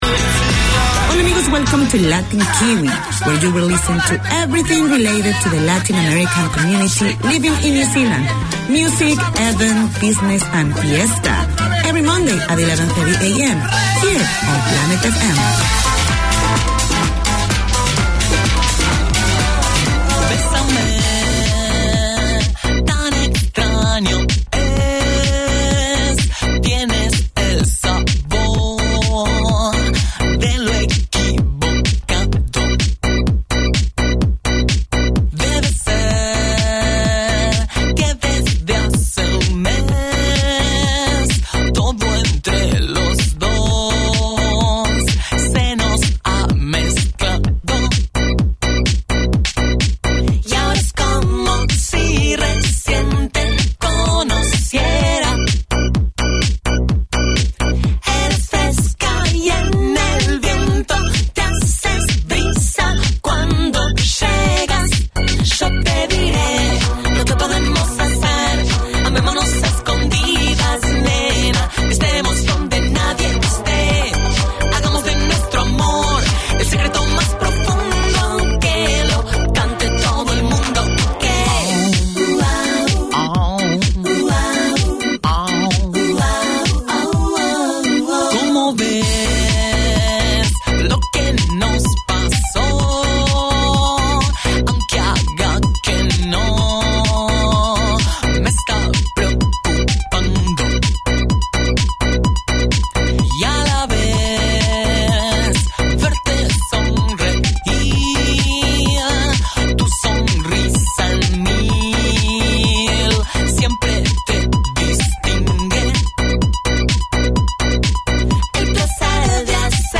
Latin Kiwi 4:25pm WEDNESDAY Community magazine Language: English Spanish Bienvenidos a todos!